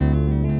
gtr_83.wav